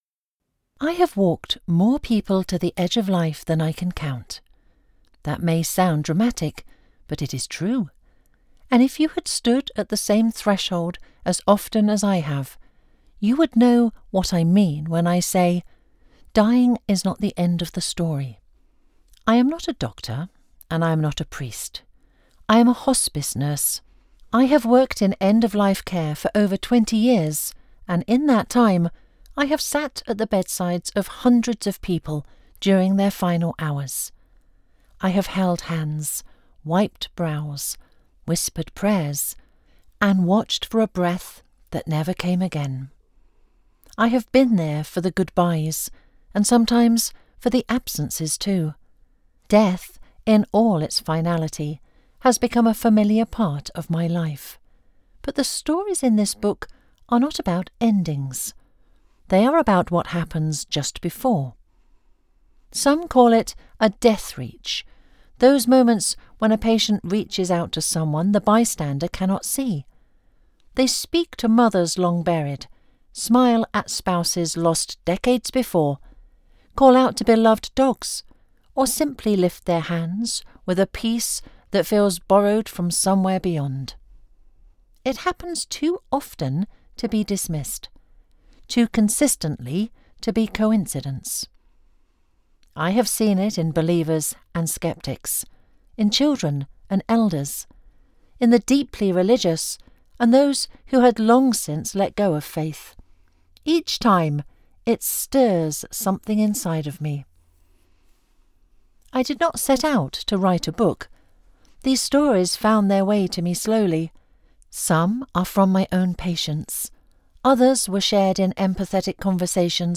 This audiobook is quiet in the best possible way.